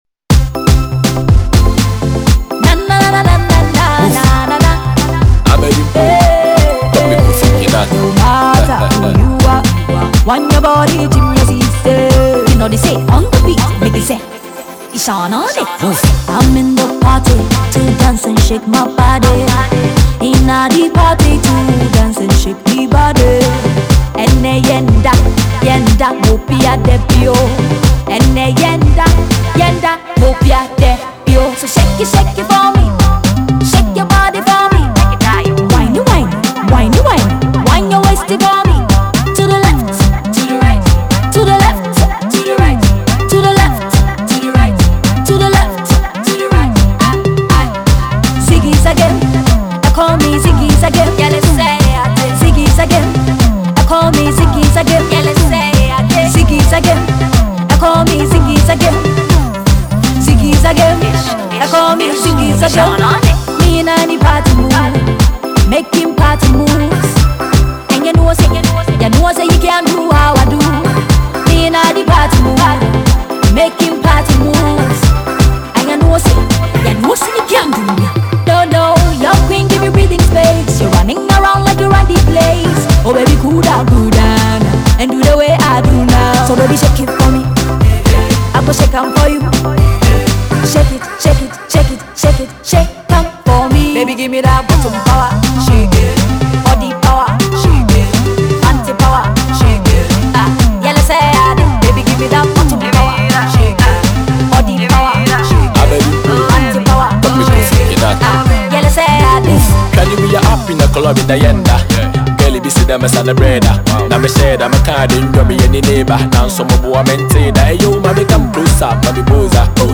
rapper
fast tempo groovy banger